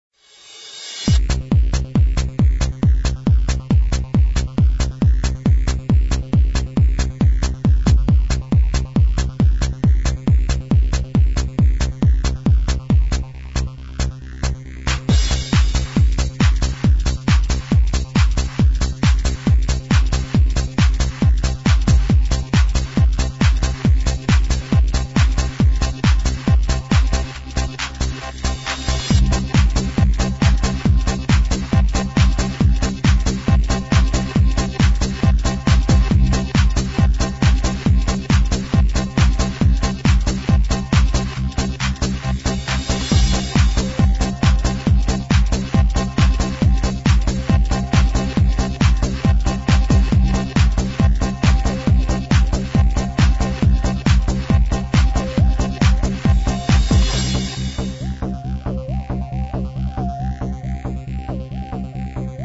Sounds like an accordian/trumpet